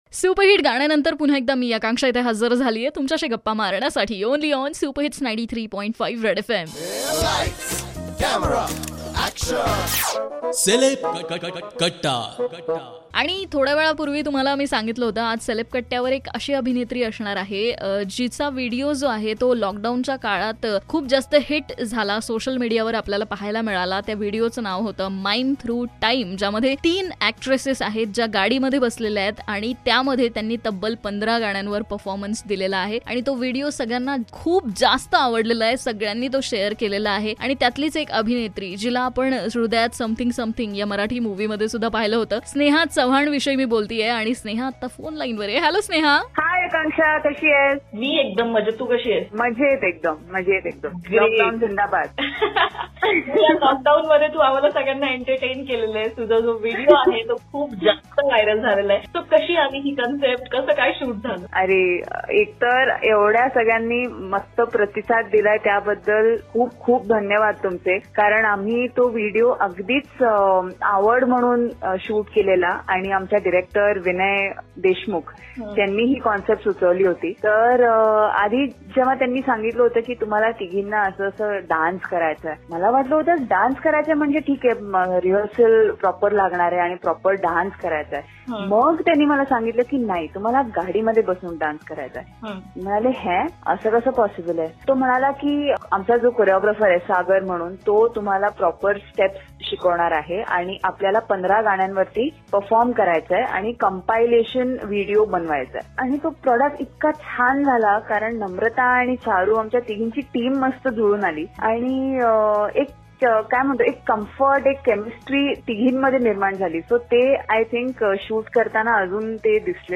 took an interview of famous actress Sneha Chavan about her viral video Mime Through Time..